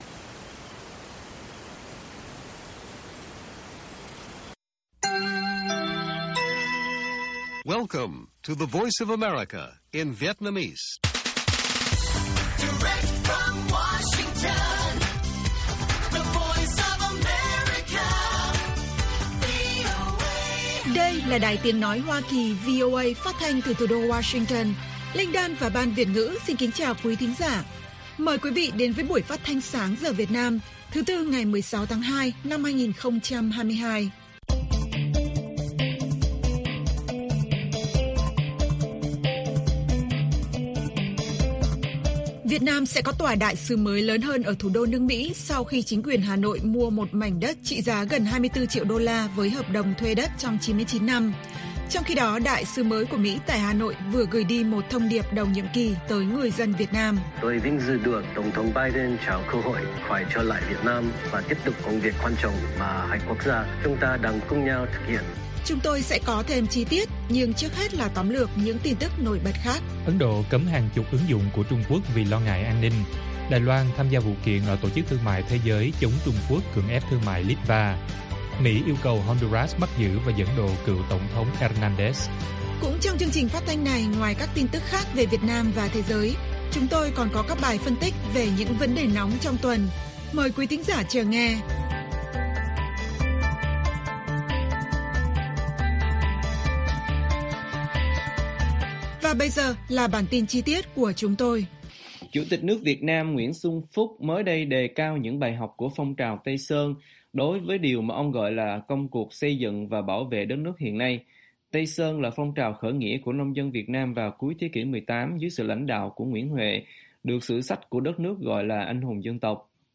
Bản tin VOA ngày 16/2/2022